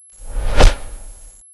SWORDMIS.WAV